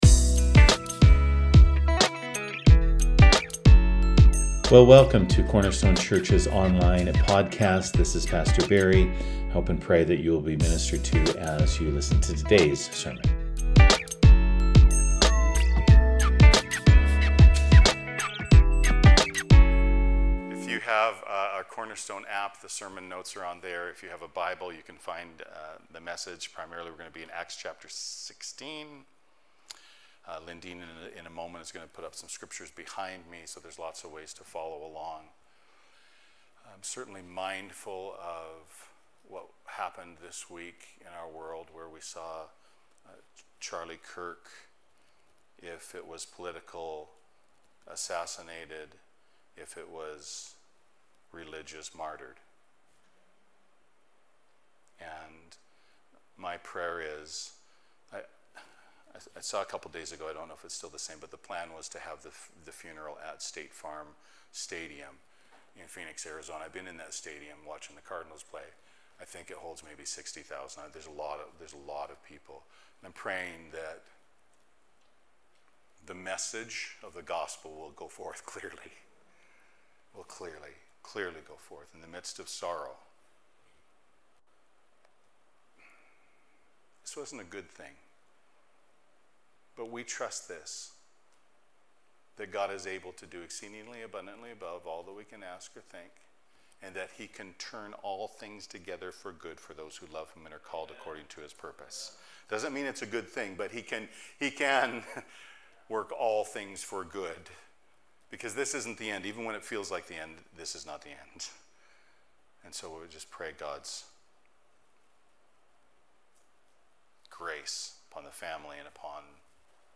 Sermons | Cornerstone Church